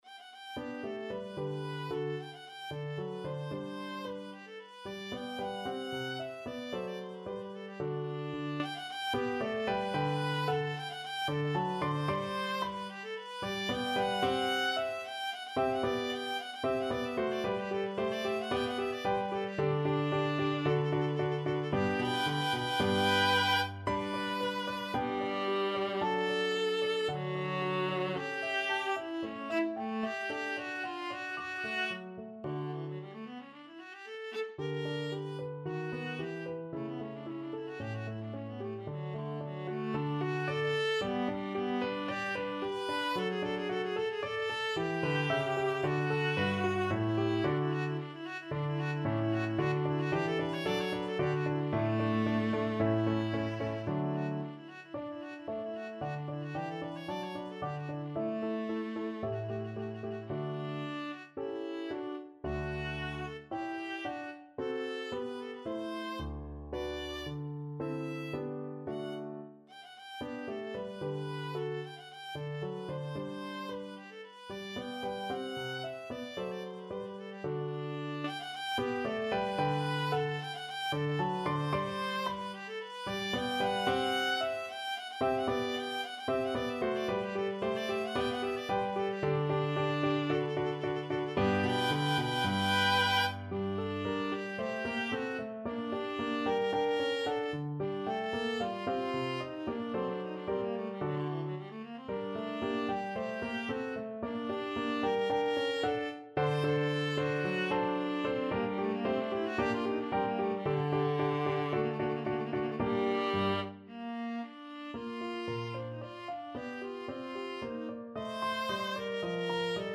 2/4 (View more 2/4 Music)
~ = 100 Allegretto (=112)
Classical (View more Classical Viola Music)